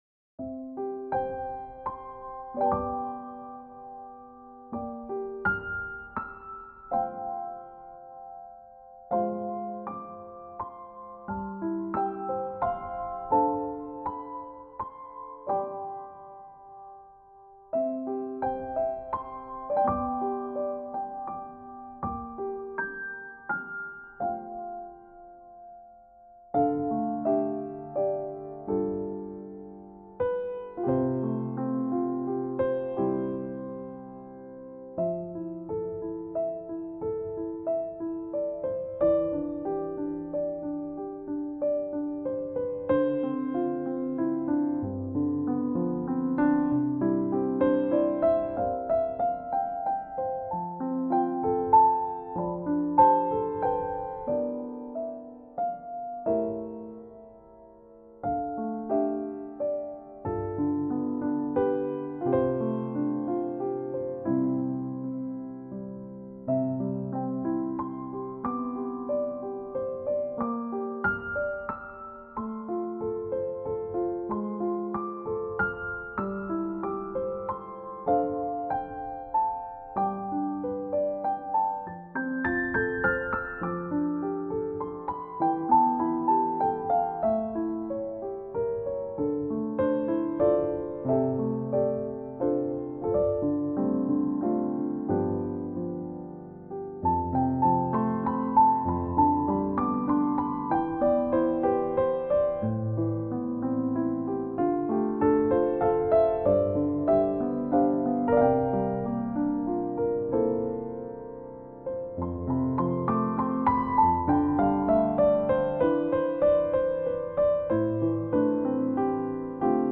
Умиротворяющие нотки пианино без слов